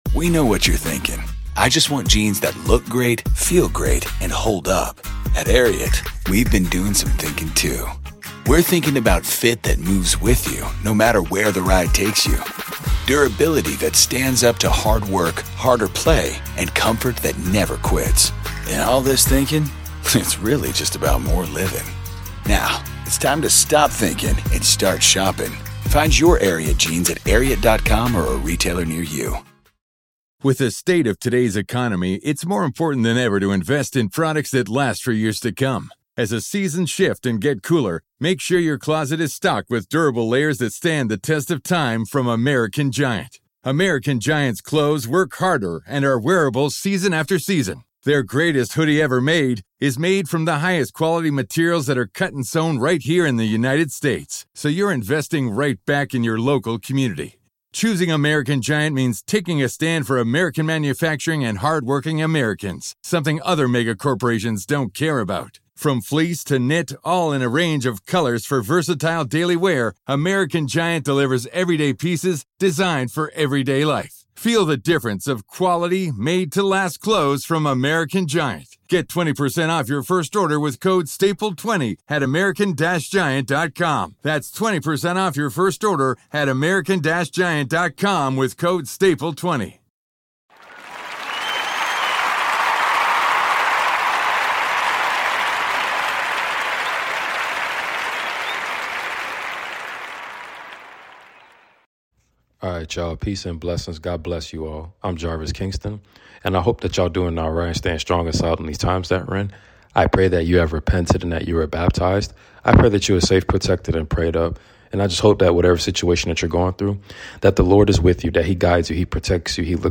Book of John chapters 1-3 reading!